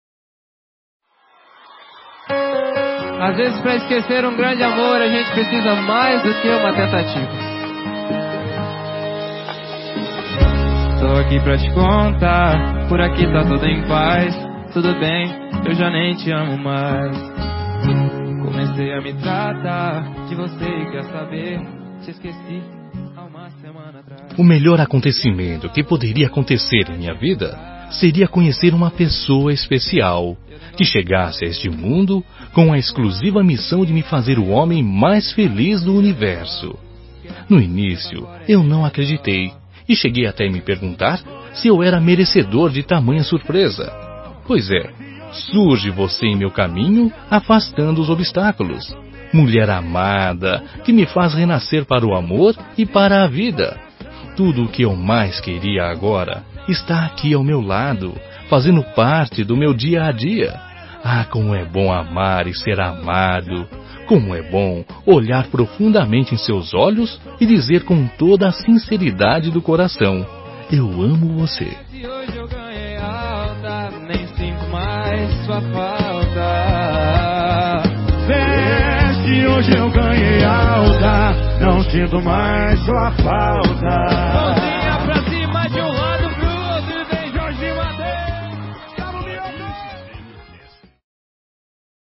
Telemensagem Declaração Romântica – Voz Masculina – Cód: 09828